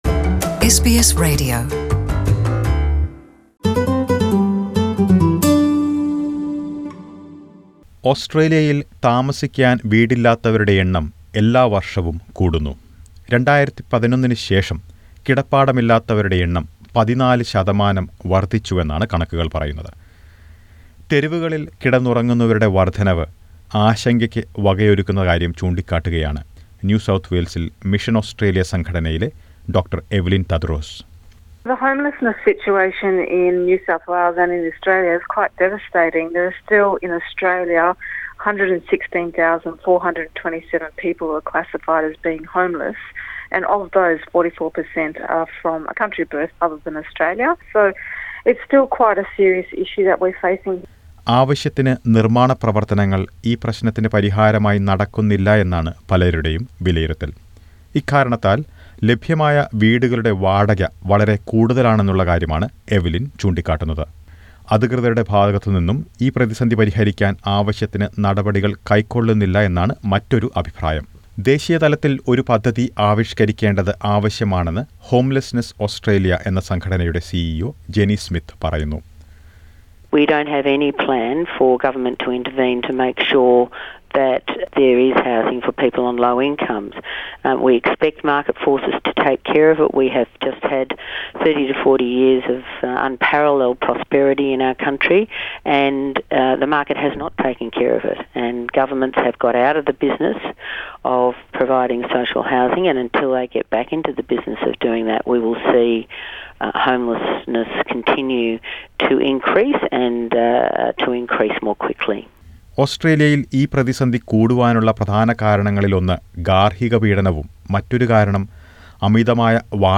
Most people do not know what to do and whom to turn to when you are about to become homeless. Listen to a report about this crisis in Australia.